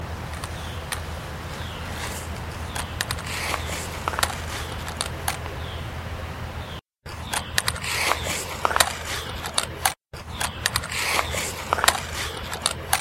Rose Hill EVP #6 - This sounds like somebody saying "Get off of me!", mixed in with me walking on the leaves.